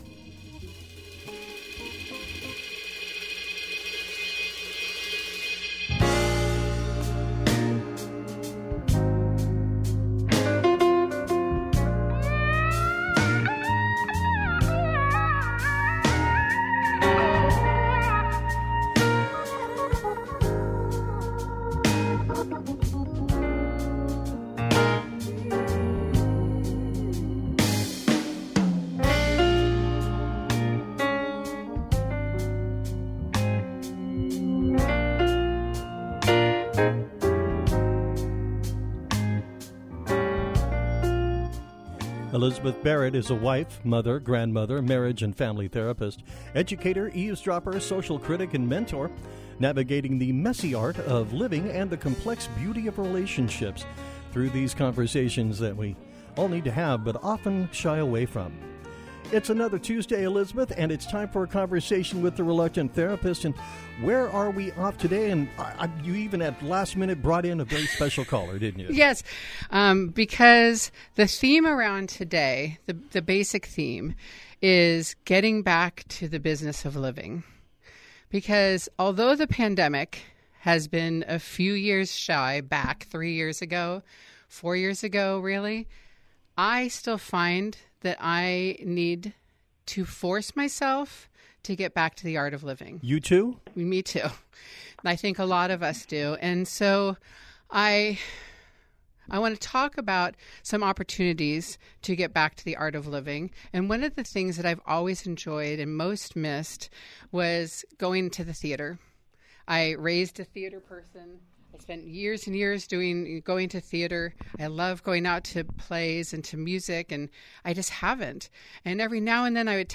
leads a conversation about the state of our collective mental health and the ways in which our helping professions are either aiding or getting in the way of our cultural well-being. With so many social services, treatments and programs available—why are we all still struggling in so many ways?